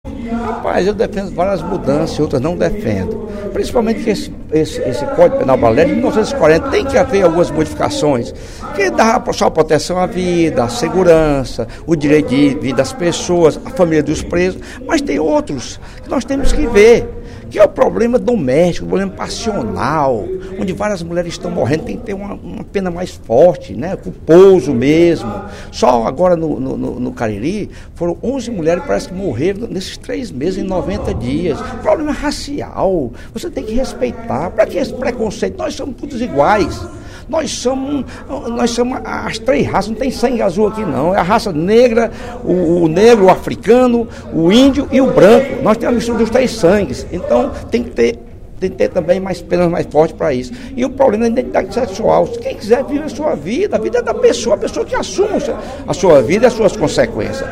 O deputado Lucílvio Girão (PMDB) apontou, em pronunciamento na sessão plenária desta quarta-feira (28/03) da Assembleia Legislativa, quais trechos do Código Penal brasileiro deveriam ser modificados pela comissão do Senado Federal que analisa a revisão do documento.